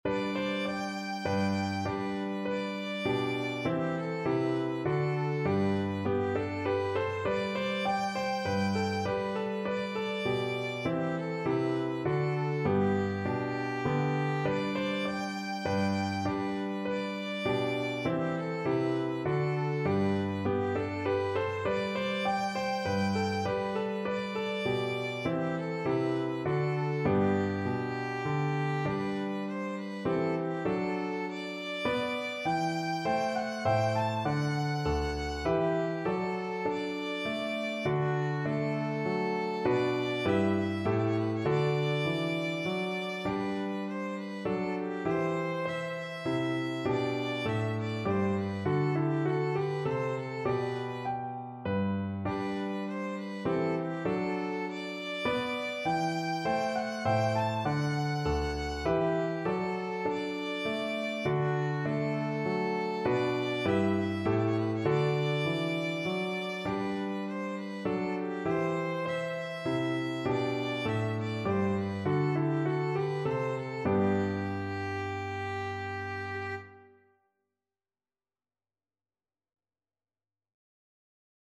Violin
G major (Sounding Pitch) (View more G major Music for Violin )
Moderato = c.100
3/4 (View more 3/4 Music)
F#5-A6
Classical (View more Classical Violin Music)